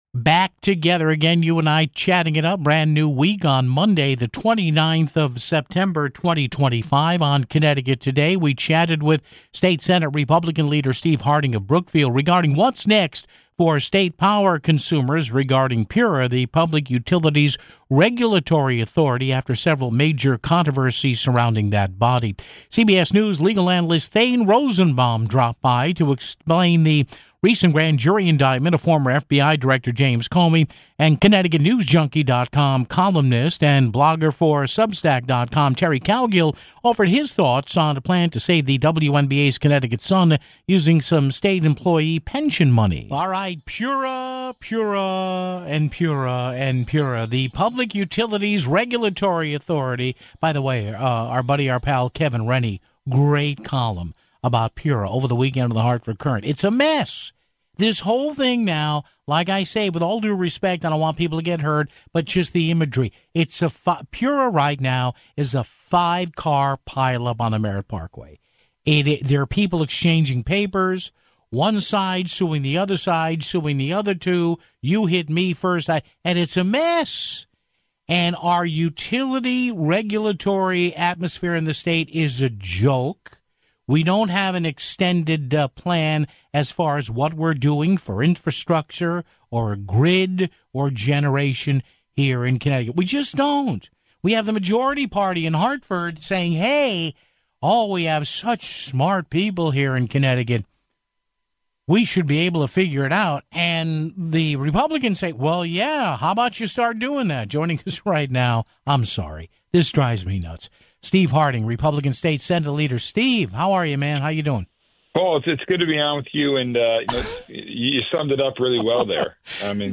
chatted with State Senate GOP leader Steve Harding, of Brookfield, regarding what's next for state power consumers regarding PURA - the Public Utilities Regulatory Authority - after several major controversies (00:42).